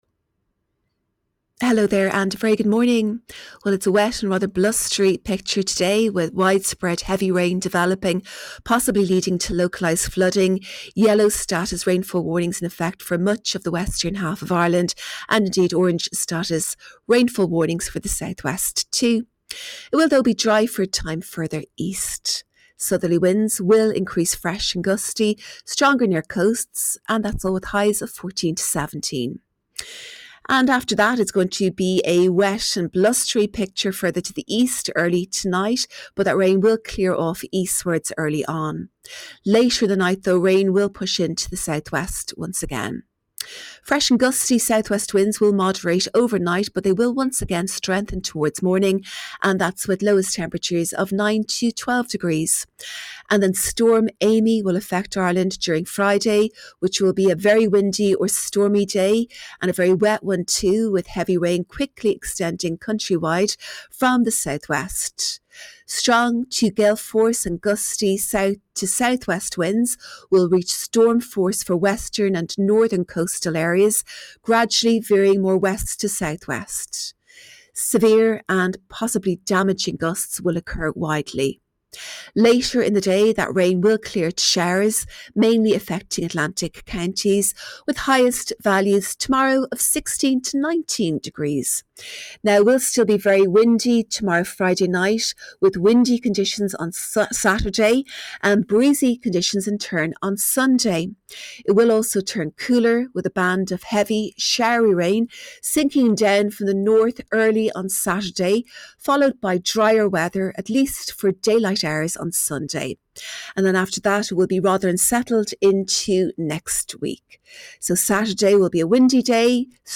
Weather Forecast from Met Éireann / Ireland's Weather 6am Thursday 2 October 2025